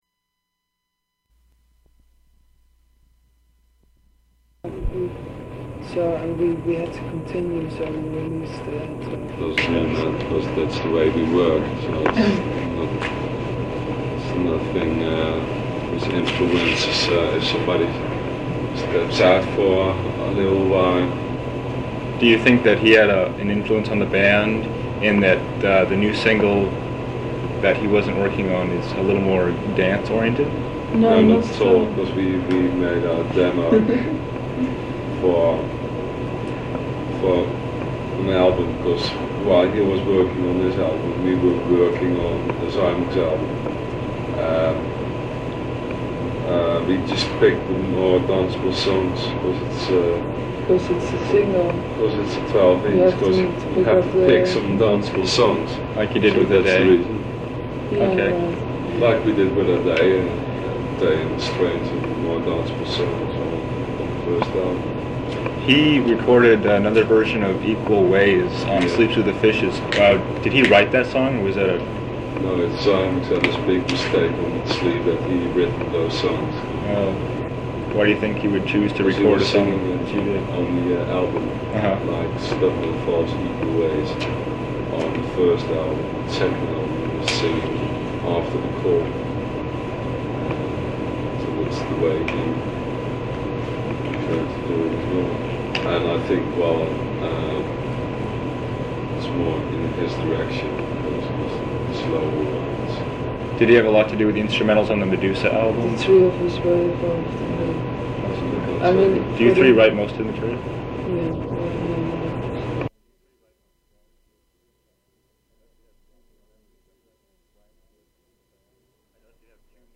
Interview with members of the band Clan of Xymox
Form of original Open reel audiotape